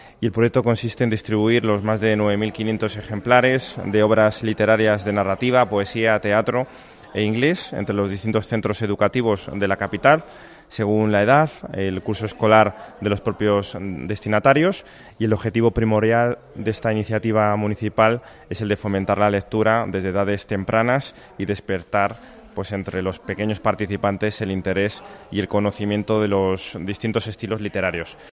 Informativos Guadalajara